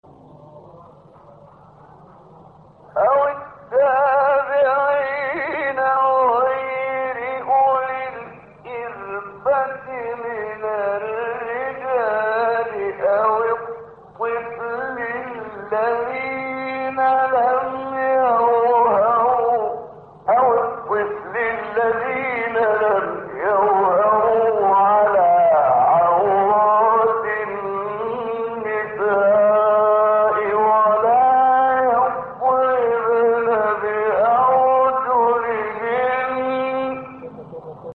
هشت فراز در مقام «سه‌گاه» با صوت محمد عمران
گروه فعالیت‌های قرآنی: فرازهایی در مقام سه‌گاه با صوت شیخ محمد عمران را می‌شنوید.